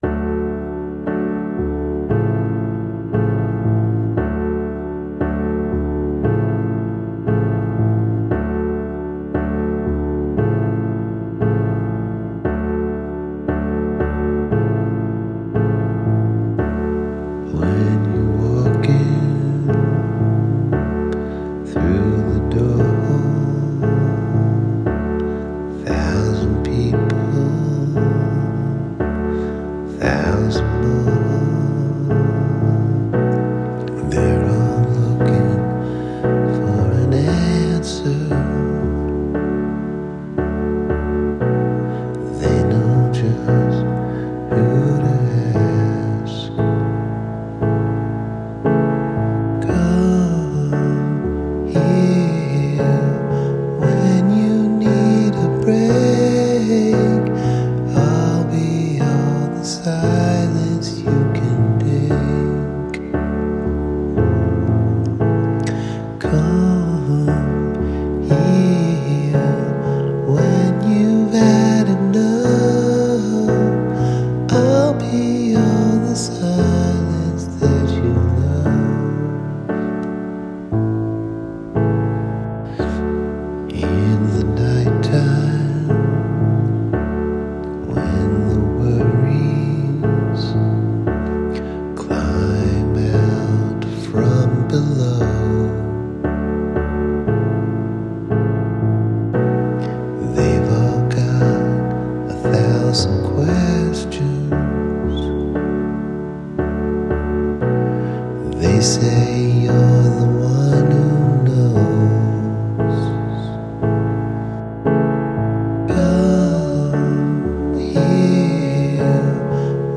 orig – piano recording ,